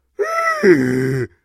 Звуки тяжелого вздоха
Комичный вздох